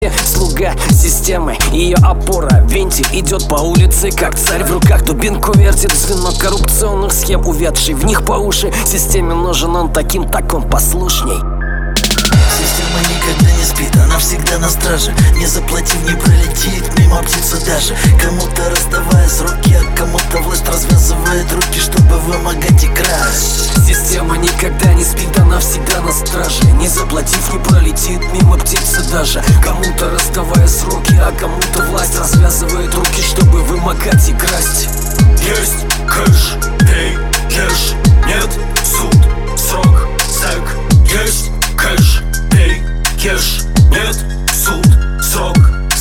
Вот вроде доделал бит, кажется не плохо..